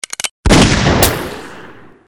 Gun Effect Sound - Bouton d'effet sonore